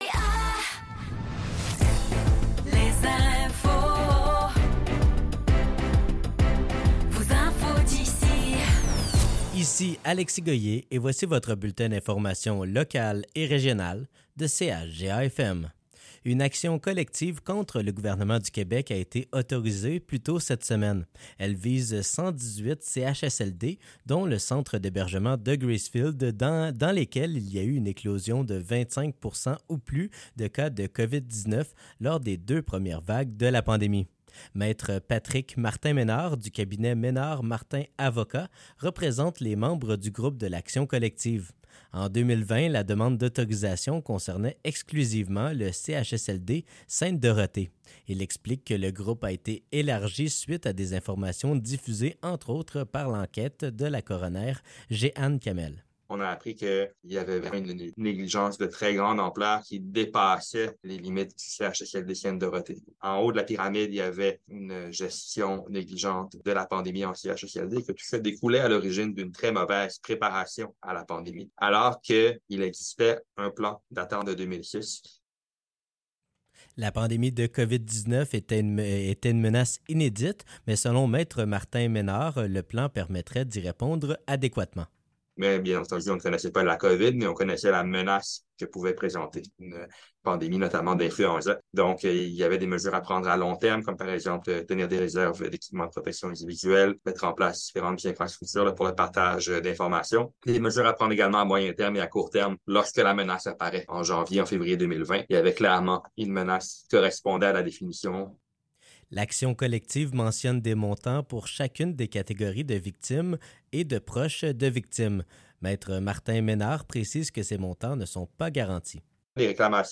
Nouvelles locales - 25 janvier 2024 - 15 h